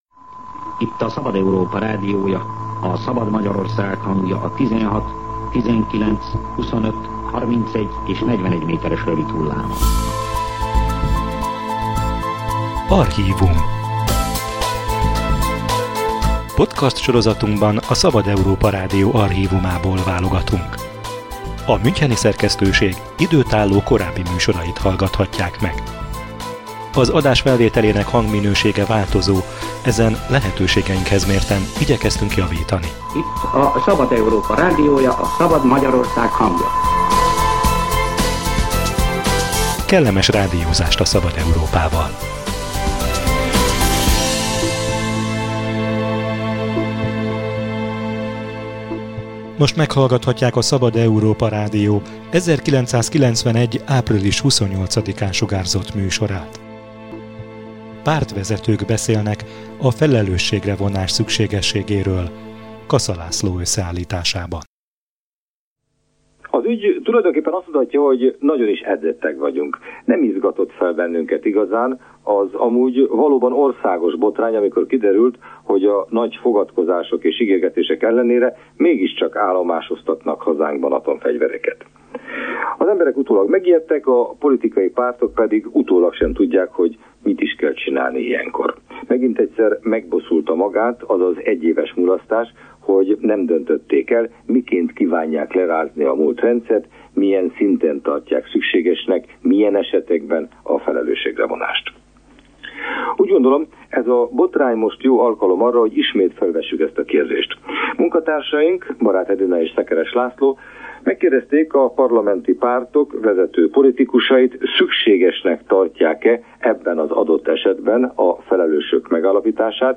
Pártvezetők beszélnek a felelősségre vonás fontosságáról – műsor a Szabad Európa Rádió archívumából